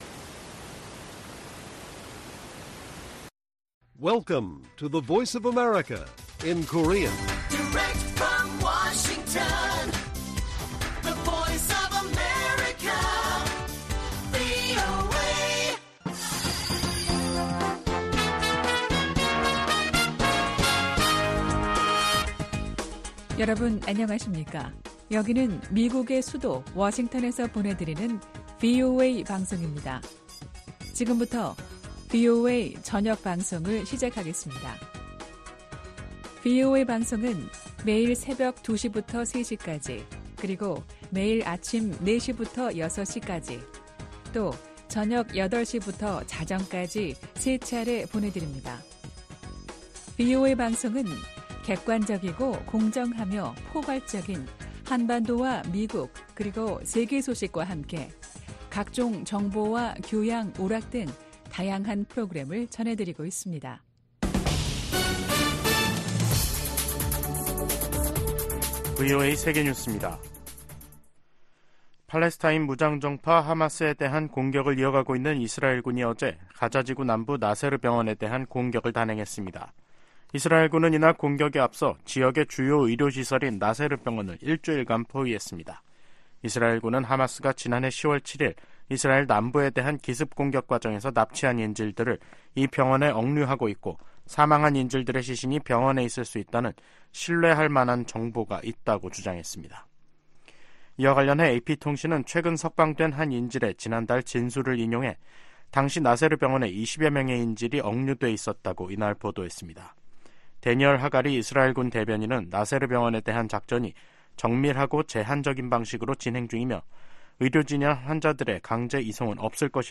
VOA 한국어 간판 뉴스 프로그램 '뉴스 투데이', 2024년 2월 16일 1부 방송입니다. 미국 고위 당국자들이 북한-러시아 관계에 우려를 나타내며 국제 협력의 중요성을 강조했습니다. 김여정 북한 노동당 부부장은 일본 총리가 평양을 방문하는 날이 올 수도 있을 것이라고 말했습니다.